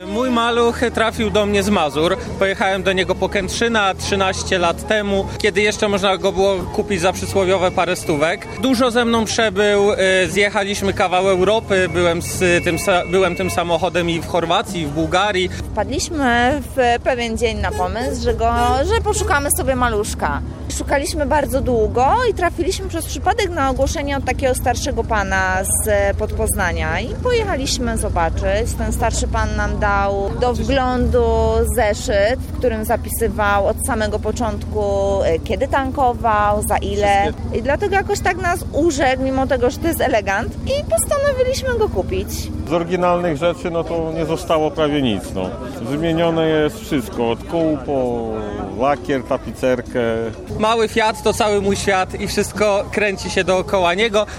Ponad sto "maluchów" zgromadziło się na rynku łódzkiej Manufaktury. Okazją do tego był coroczny zlot i Parada Maluchów.